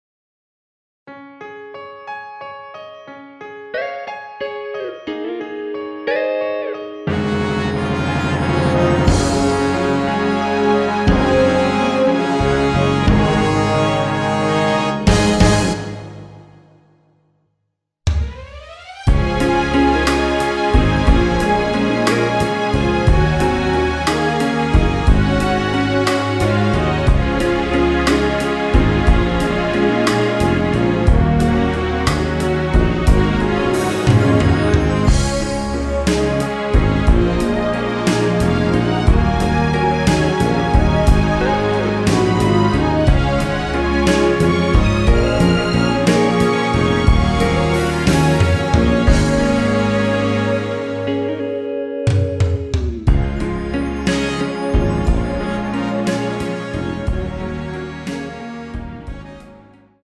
Db
◈ 곡명 옆 (-1)은 반음 내림, (+1)은 반음 올림 입니다.
앞부분30초, 뒷부분30초씩 편집해서 올려 드리고 있습니다.